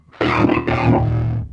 描述：为作为口语的阿拉巴马创造了声音